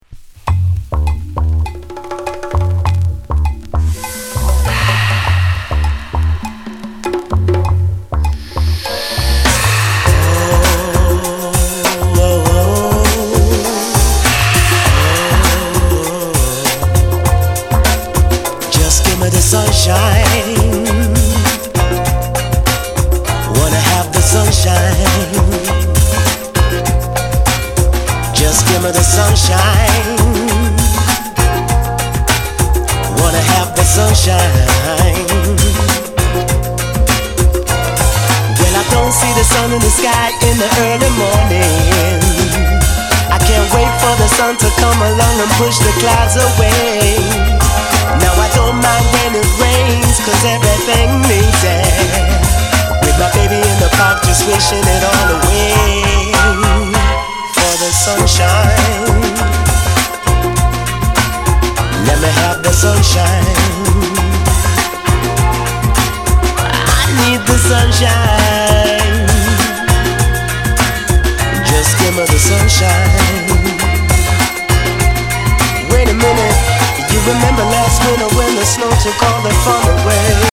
Genre:  Acid Jazz